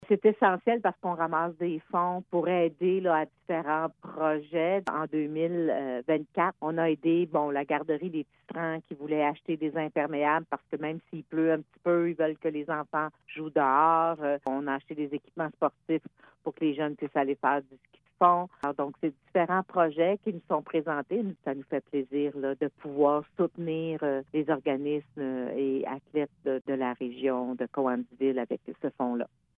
La mairesse de Cowansville, Sylvie Beauregard se dit reconnaissante de la générosité de la communauté et du milieu des affaires qui a assuré le succès de l’évènement.